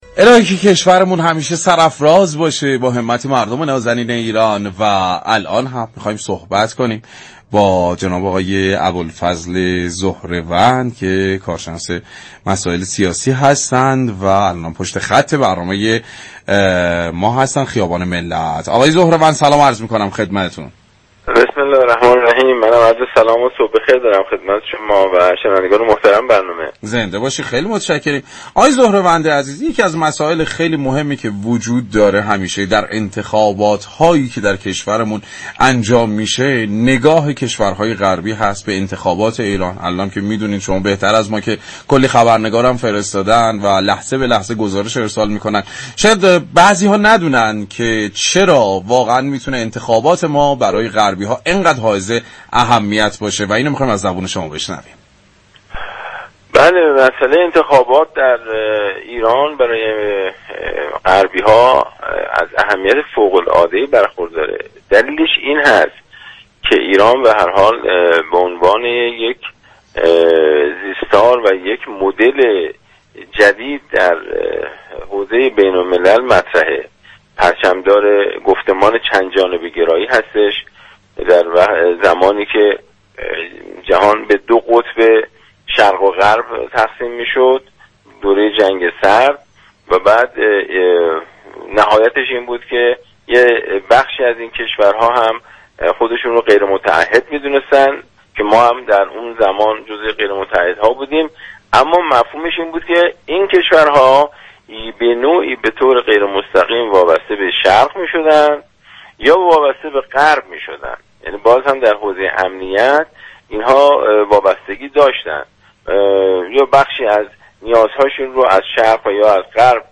كارشناس مسائل سیاسی در گفتگو با برنامه انتخاباتی خیابان ملت